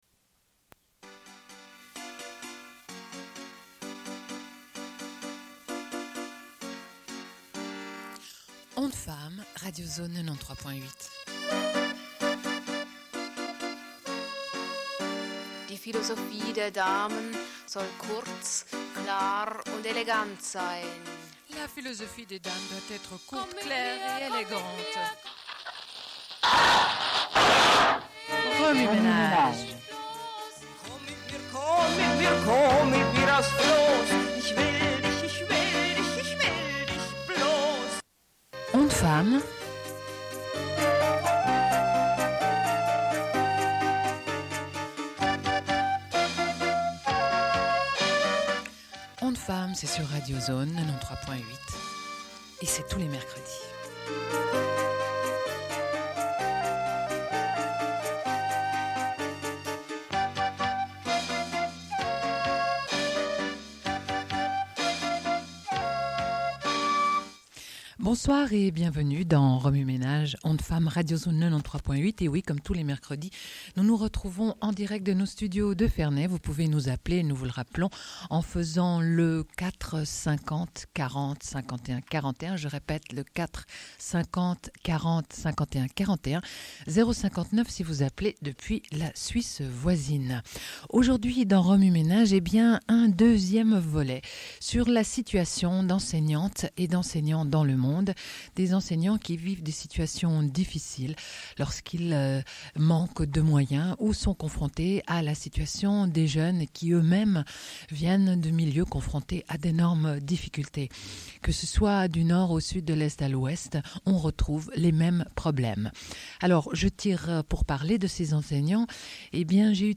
Diffusion d'un entretien réalisé à l'école Bait Fejar en Palestine, avec des enseignant·es en grève au moment de l'entretien (avril 1997).